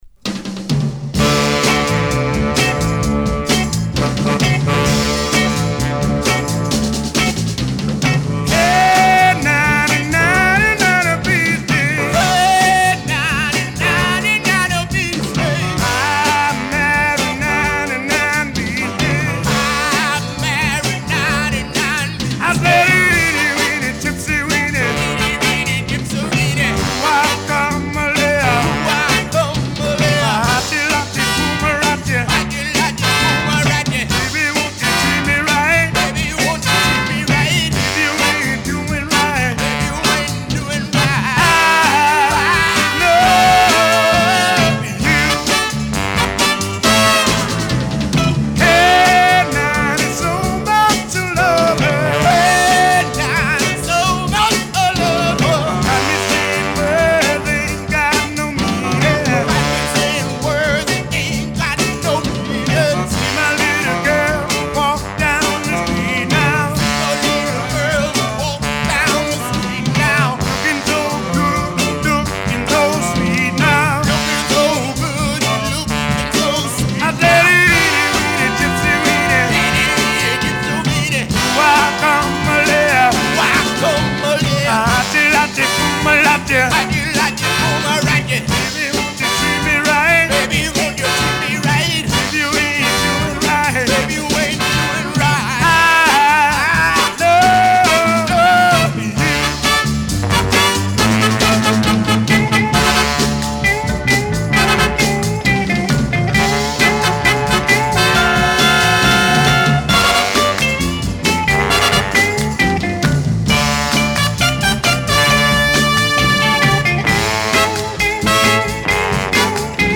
分厚い演奏に支えられた、フード・テーマのノヴェルティ・ナンバー。